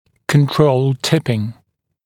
[kən’trəuld ‘tɪpɪŋ][кэн’троулд ‘типин]контролируемый наклон